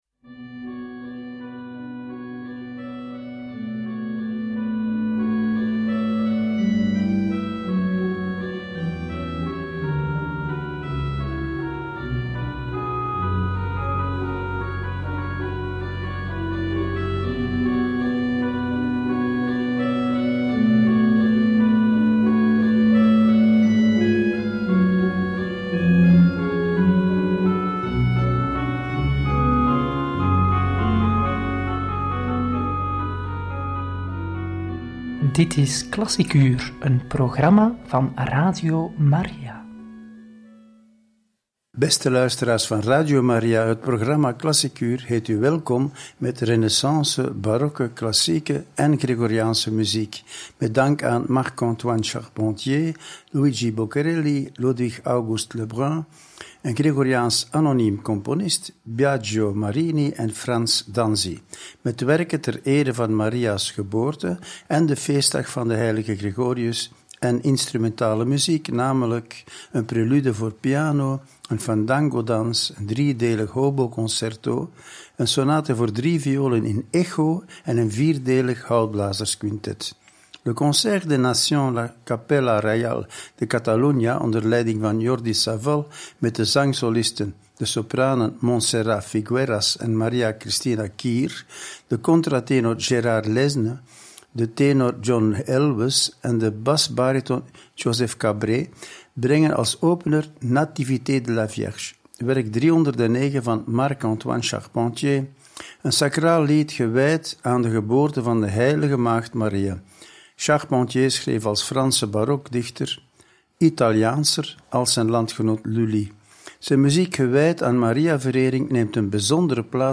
Klassiekuur heet u welkom met renaissance, barokke, klassieke en gregoriaanse muziek!
klassiekuur-heet-u-welkom-met-renaissance-barokke-klassieke-en-gregoriaanse-muziek.mp3